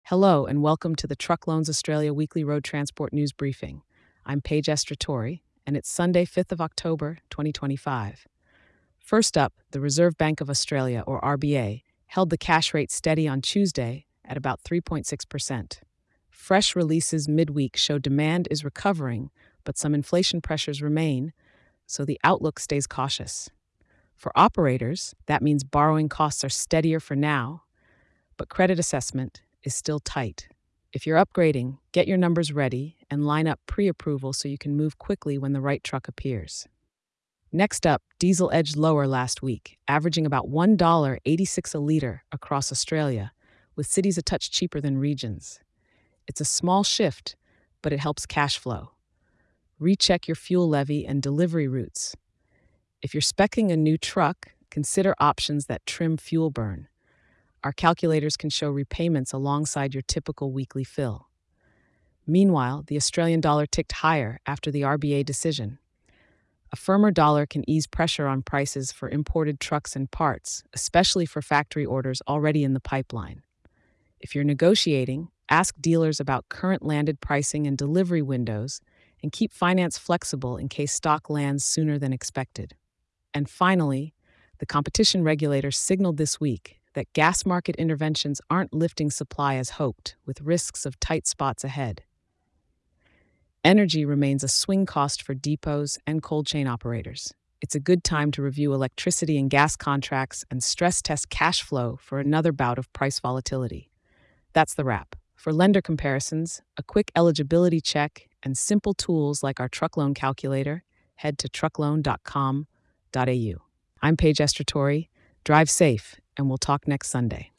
EPISODE 969 | Truck Loans Australia Weekly Road Transport News Briefing | Sun, 7th Dec 2025